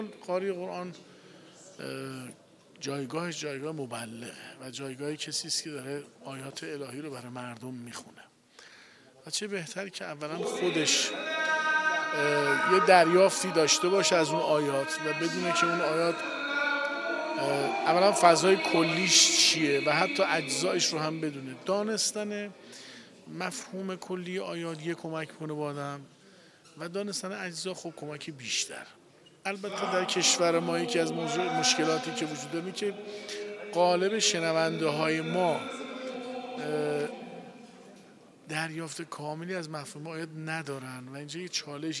با این وجود در حاشیه این نشست، ویژگی‌هایی که یک قاری مبلغ باید داشته باشد از سه تن از کارشناسان پیشکسوت قرآن جویا شدیم که در ادامه اظهار نظر ایشان را می‌خوانید: